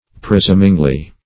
presumingly - definition of presumingly - synonyms, pronunciation, spelling from Free Dictionary Search Result for " presumingly" : The Collaborative International Dictionary of English v.0.48: Presumingly \Pre*sum"ing*ly\, adv. Confidently; arrogantly.